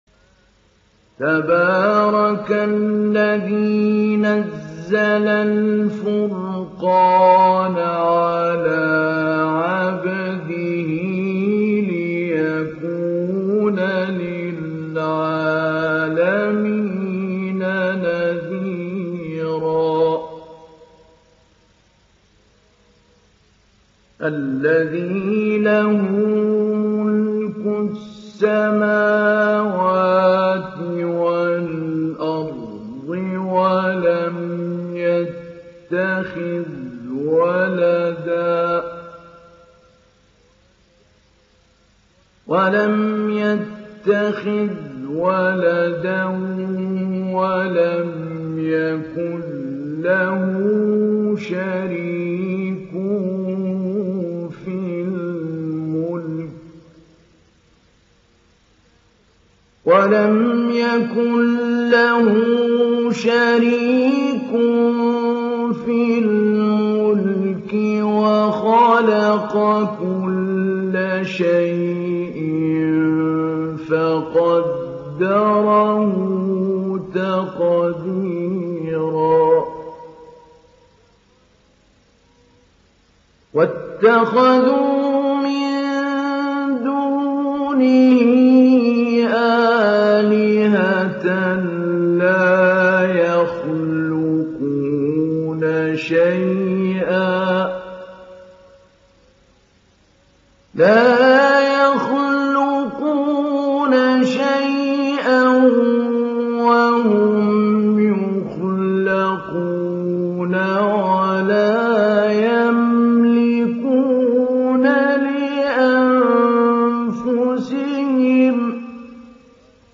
تحميل سورة الفرقان mp3 بصوت محمود علي البنا مجود برواية حفص عن عاصم, تحميل استماع القرآن الكريم على الجوال mp3 كاملا بروابط مباشرة وسريعة
تحميل سورة الفرقان محمود علي البنا مجود